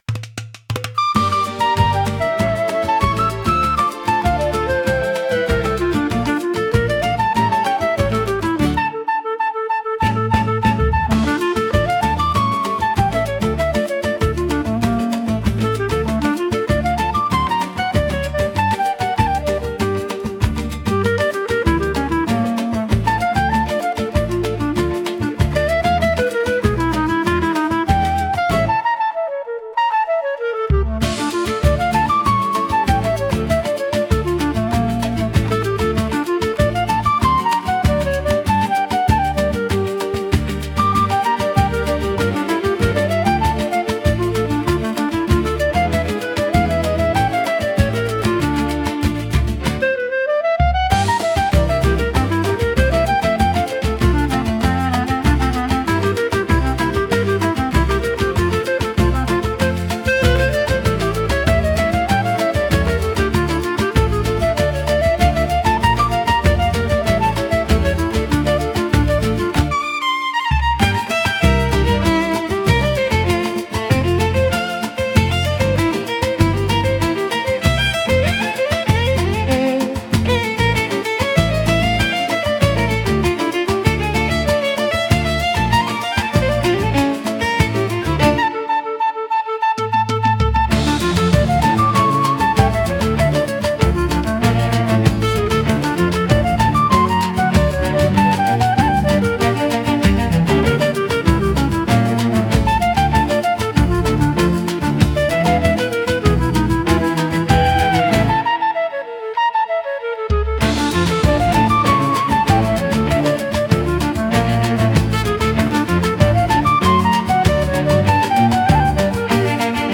música e arranjo: IA) instrumental 5